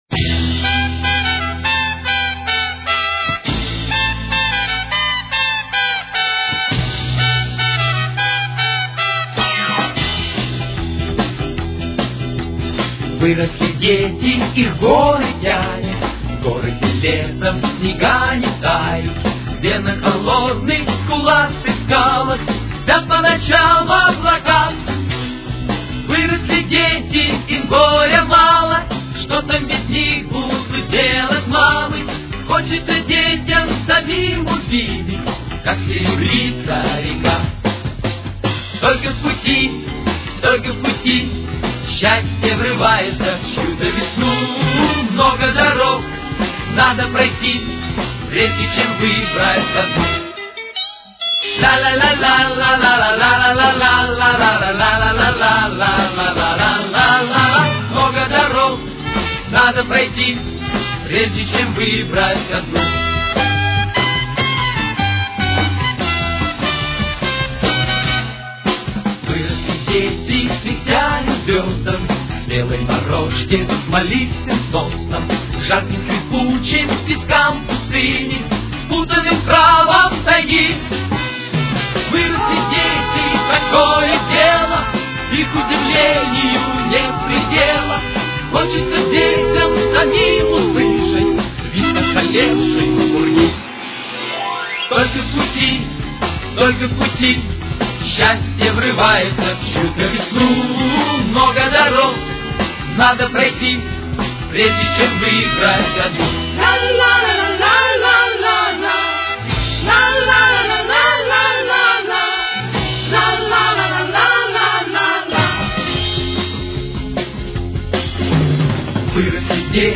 с очень низким качеством